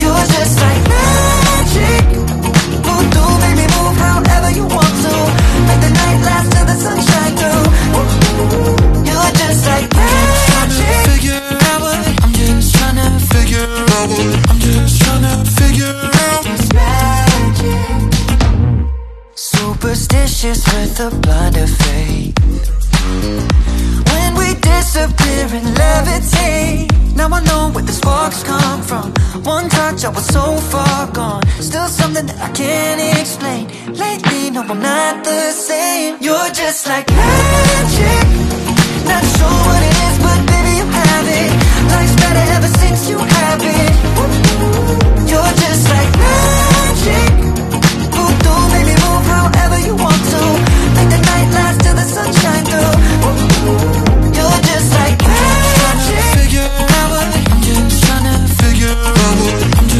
Back patio + pool side outdoor weather-proof sound system